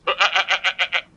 Index of /fastdownload/r_animals/files/goat